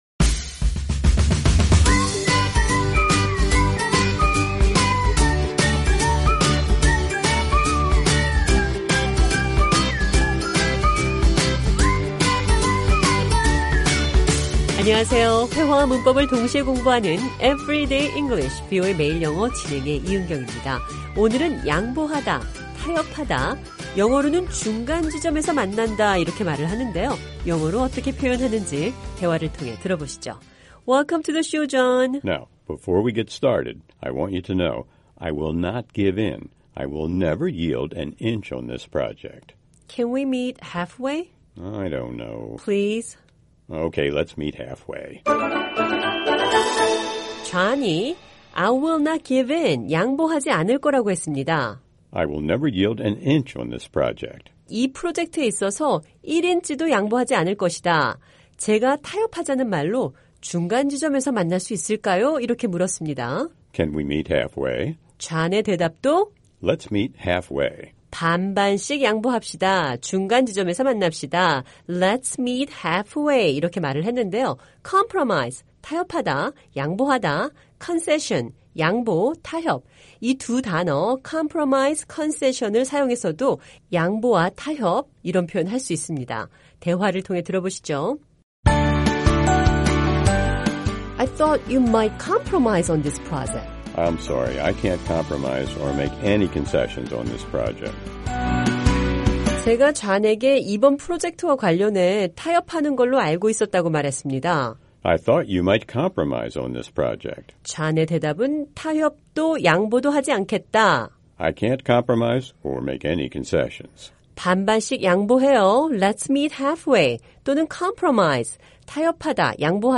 오늘은 양보하다, 타협하다, 영어로는 중간지점에서 만난다라고 하는데요 영어로 어떻게 표현하는지 대화를 통해 들어보시죠.